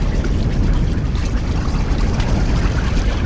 OZ_Geyser_BuildUp_Loop.ogg